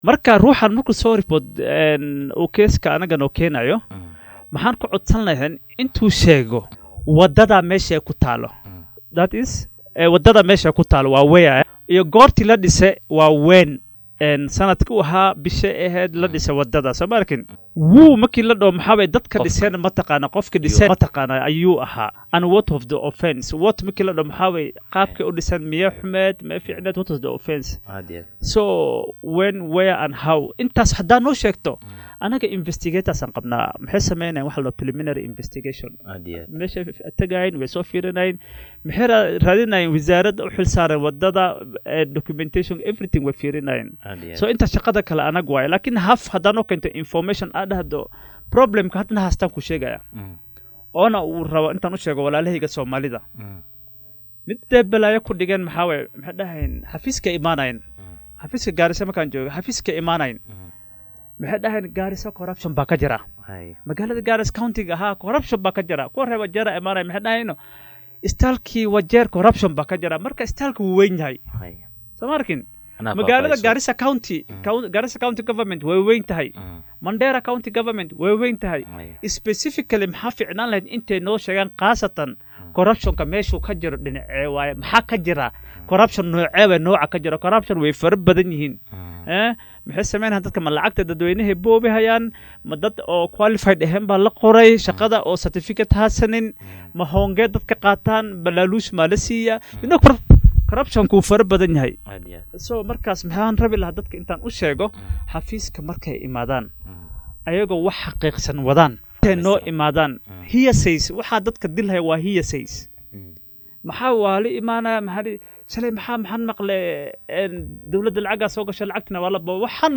Sarkaal sare oo ka tirsan guddiga anshaxa iyo ladagaalnka Musuqmaasuqa ee EACC oo saaka marti ku ahaa barnaamijka Hoggaanka Star ayaa inooga warbixiyay waxyaabo badan oo ku saabsan la dagaalanka Musuqmaasuqa.